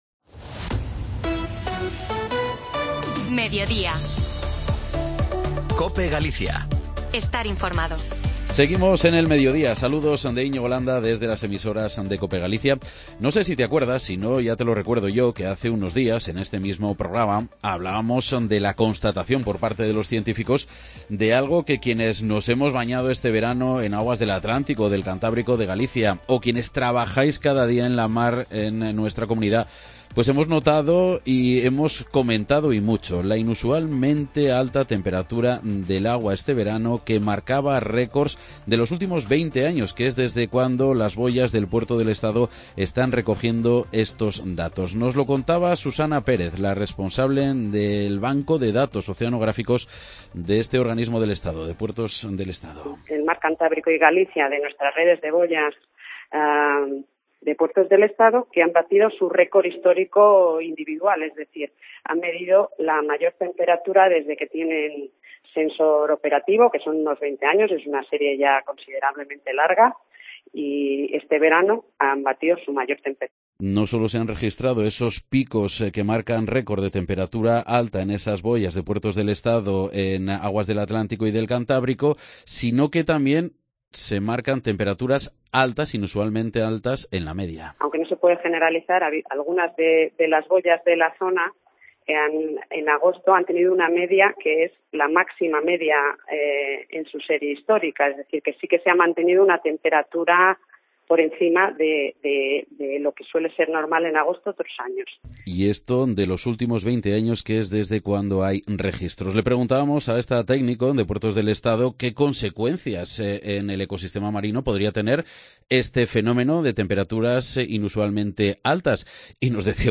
Entrevista completa con la investigadora del IEO